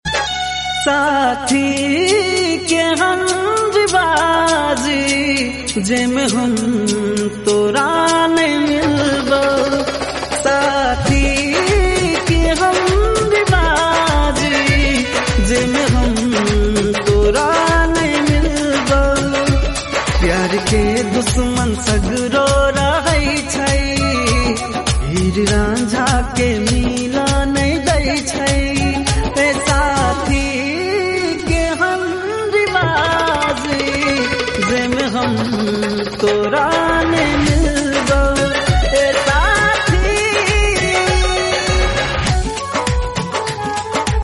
maithili sad